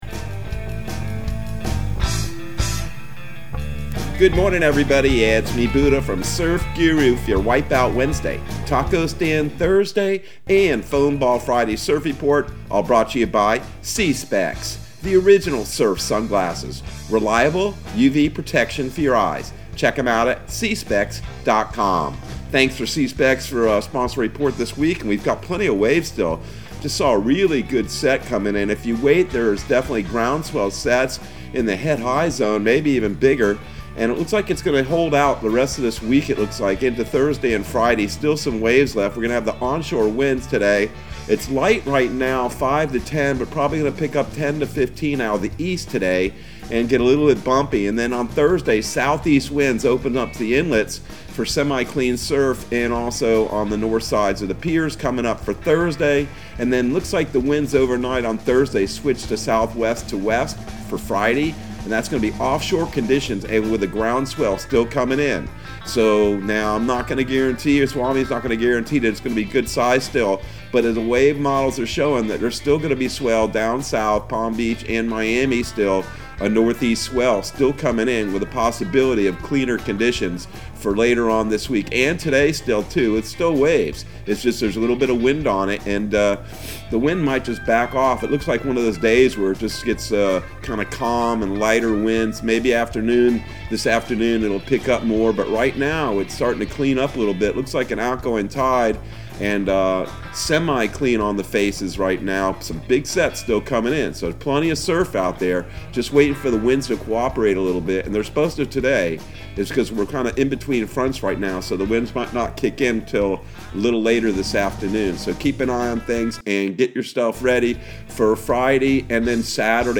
Surf Guru Surf Report and Forecast 11/10/2021 Audio surf report and surf forecast on November 10 for Central Florida and the Southeast.